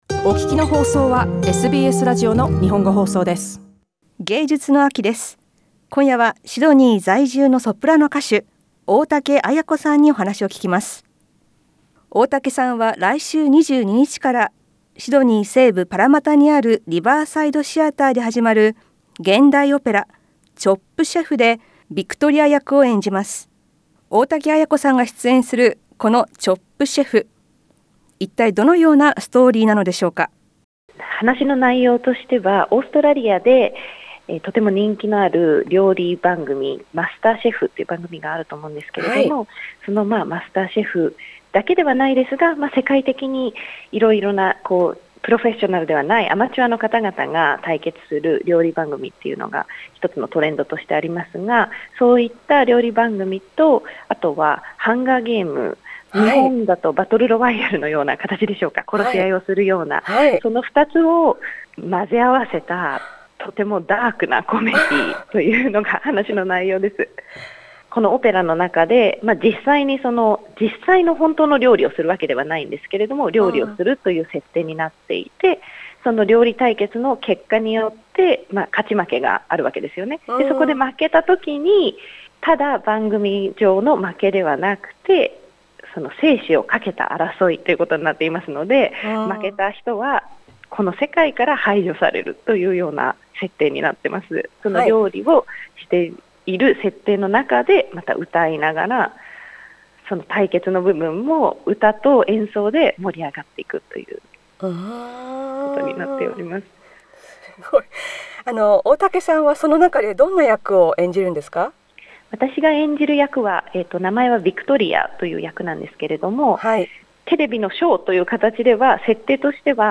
インタビューでは、「Chop Chef」についてや、現代オペラとしてのおもしろさ、そしてその見どころなどを聞きました。